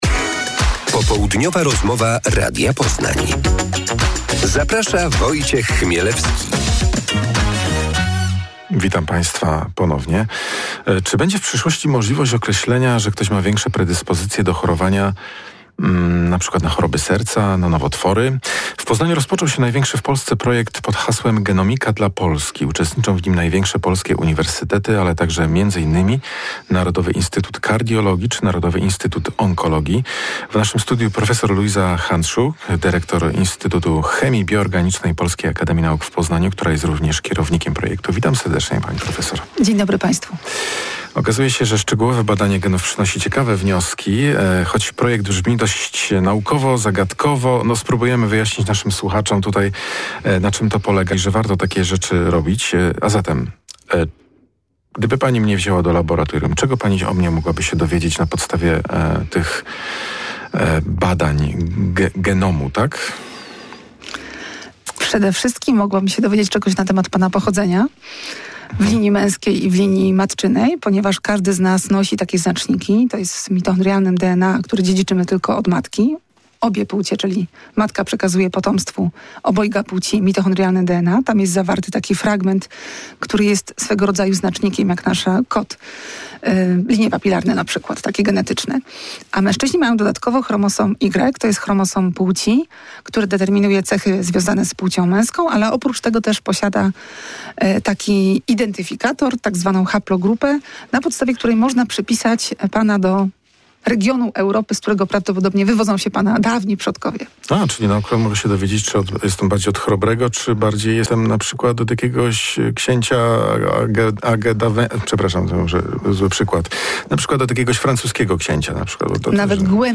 Popołudniowa rozmowa Radia Poznań